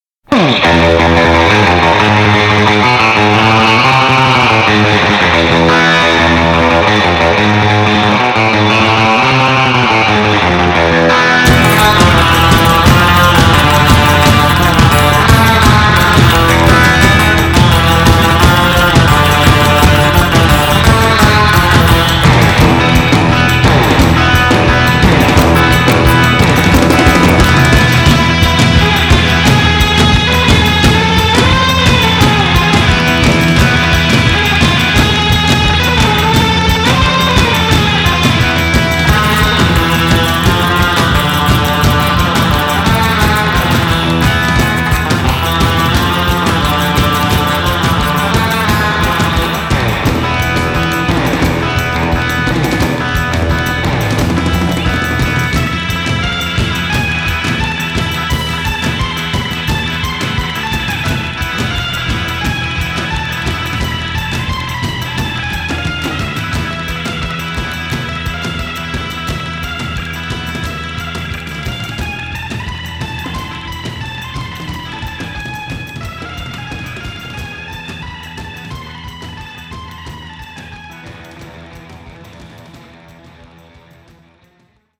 He developed a distinct, blazingly fast, single-note staccato style of playing the guitar that he made his own.